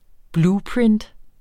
Udtale [ ˈbluːˌpɹend ]